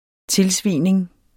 Udtale [ -ˌsviˀneŋ ]